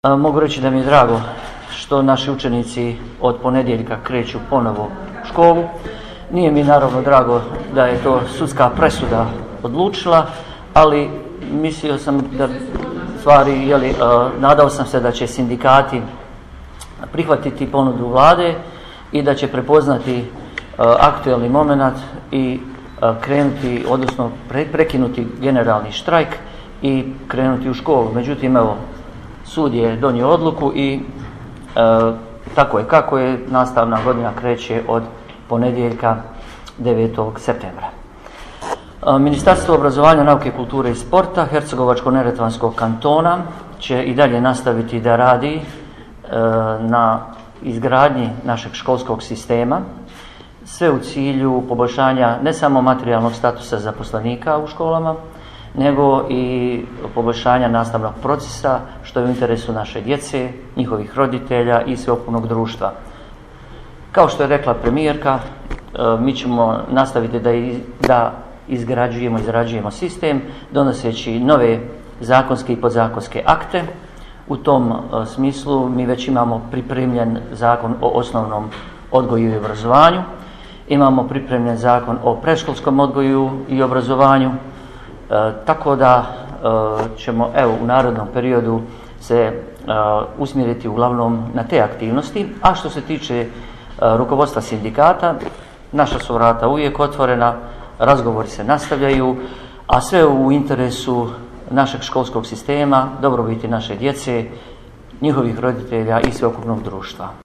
Audio zapisi izjava predsjednice Buhač i ministra Velagića dostupni su u privitku:
Audio: Ministar Adnan Velagić